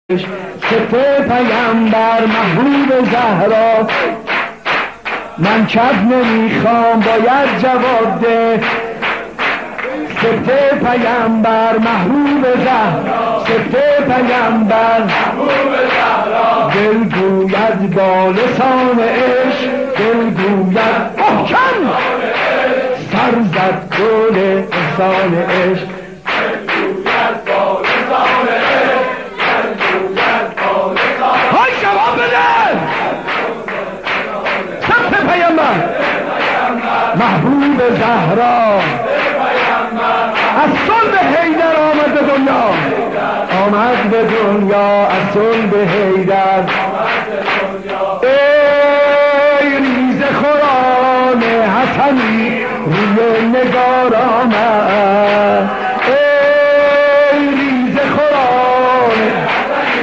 مولودی خوانی
در میلاد امام حسن مجتبی (ع)